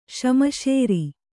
♪ śamaśeri